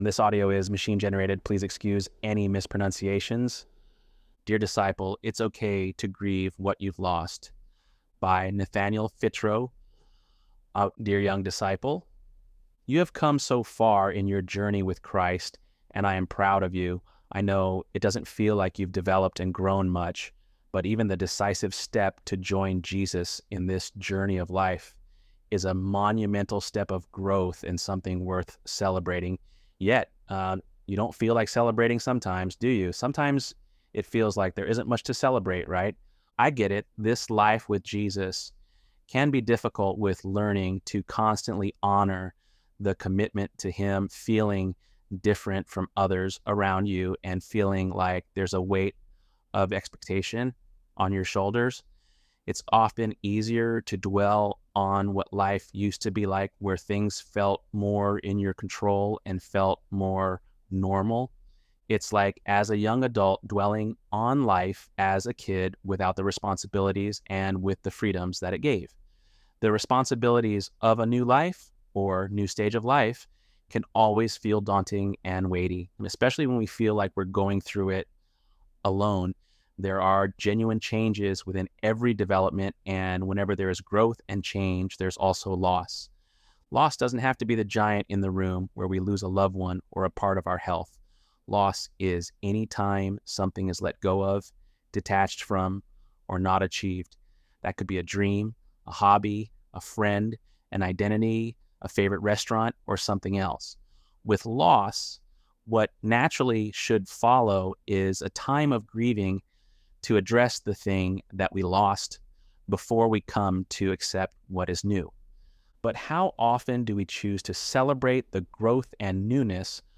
ElevenLabs_9.10.mp3